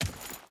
Dirt Chain Run 4.ogg